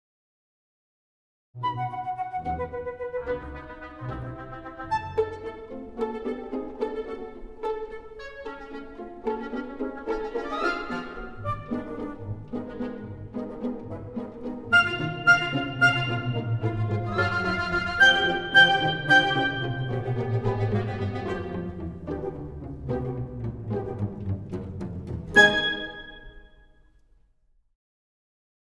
Cinematic woodwind samples
Versatile and detailed